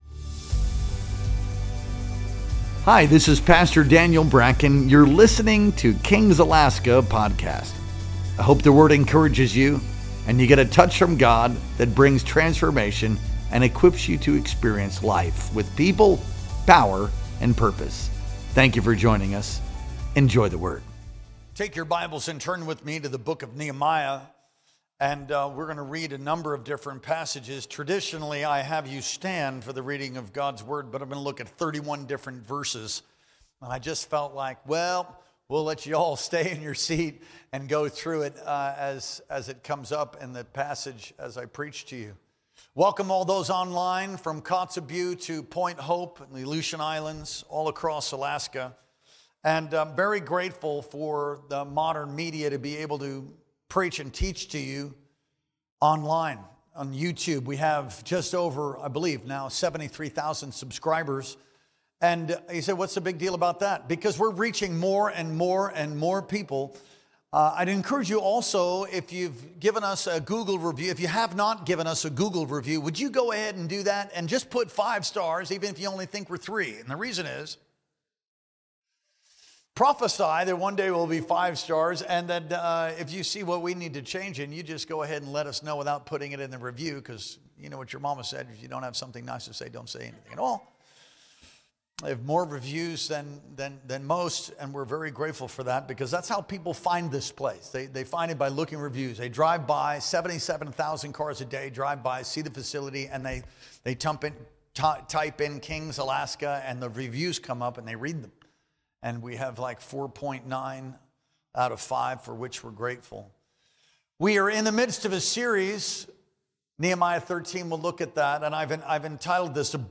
Our Sunday Worship Experience streamed live on March 30th, 2025.